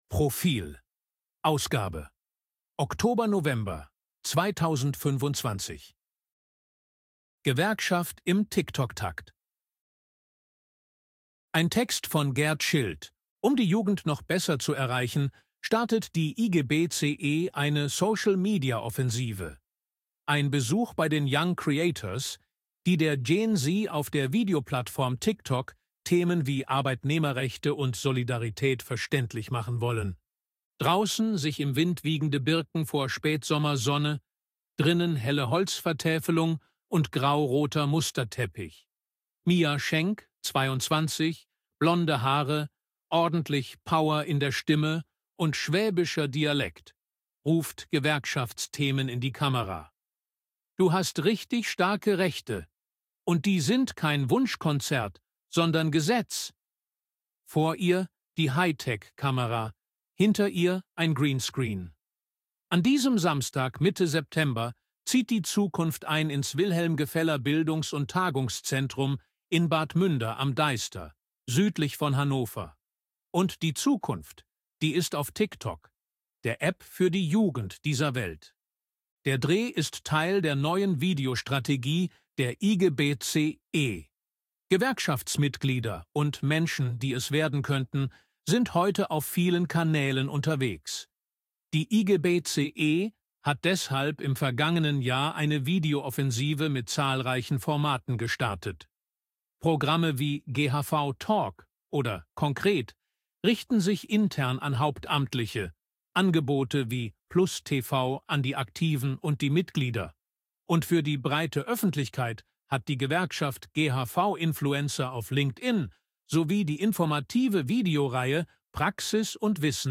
ElevenLabs_255_KI_Stimme_Mann_Reportage.ogg